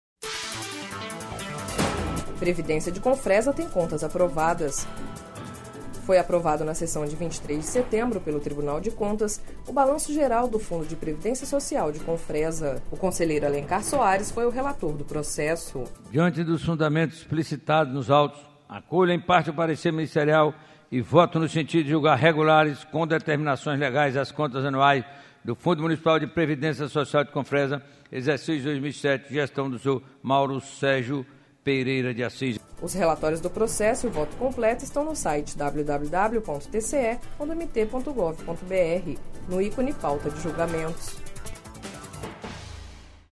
Sonora: Alencar Soares – conselheiro do TCE-MT